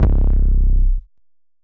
808 (Everything We Need).wav